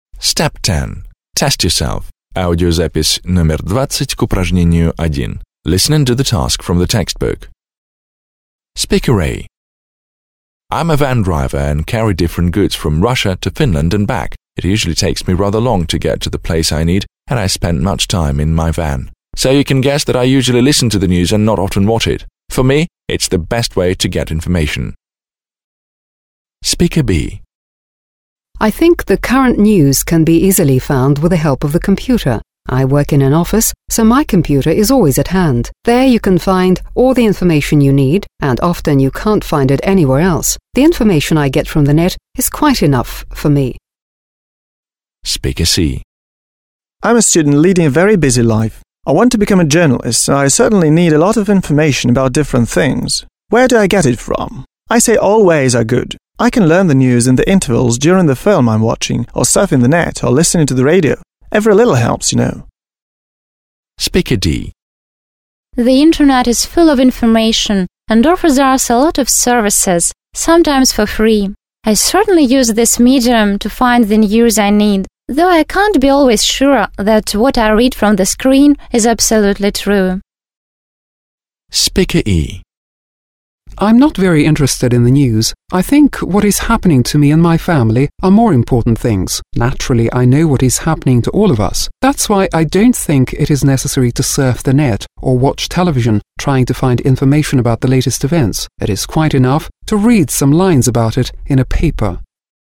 1. Вы услышите, как пять человек рассказывают о том, как они узнают новости и получают информацию. Послушайте, (20), и сопоставьте утверждения (1-6) с тем, что говорят люди (a-e).